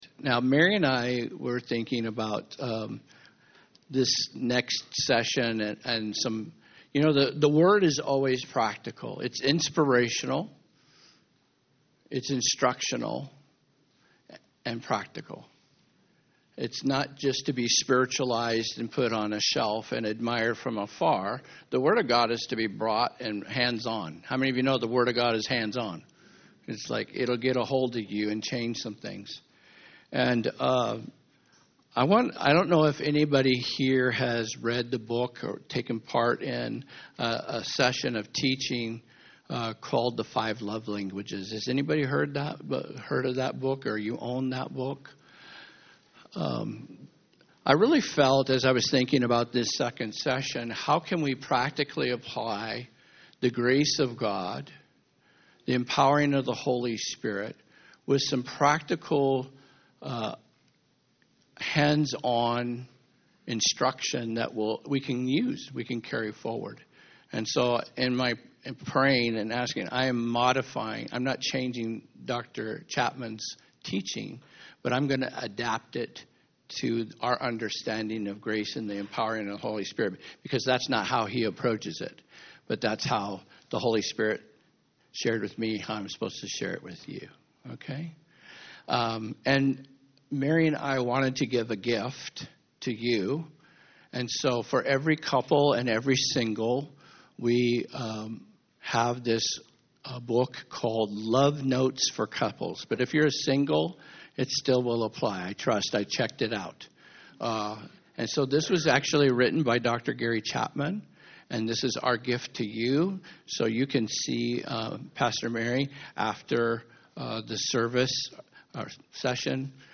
Second session of the VCC Marriage Conference 2025